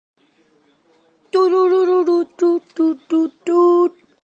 Play and download TUNG TUNG BOOM sound effect.